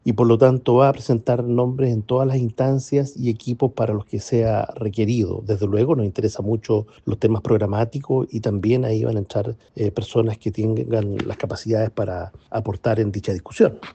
Pese a ello, Jaime Quintana, timonel del PPD, partido en el que hay quienes han planteado pedir garantías programáticas para sumarse al comando, aseguró que “cumplirán con sus compromisos”.